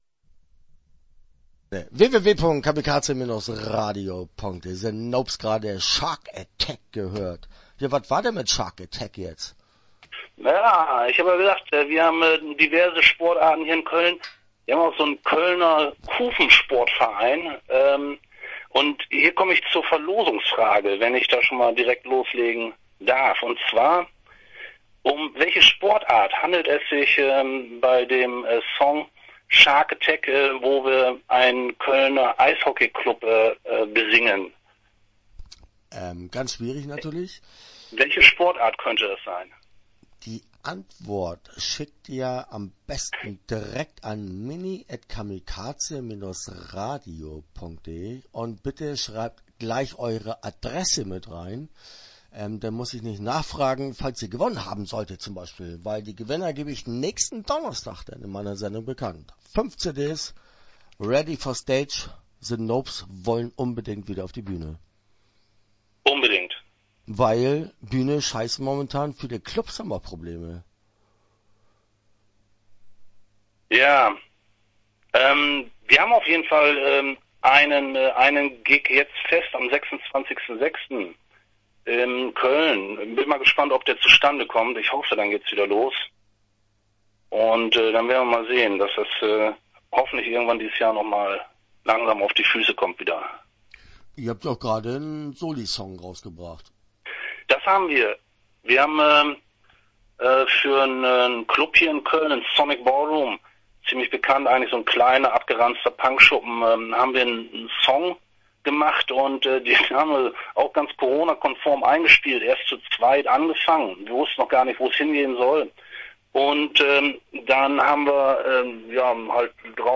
Start » Interviews » The Nopes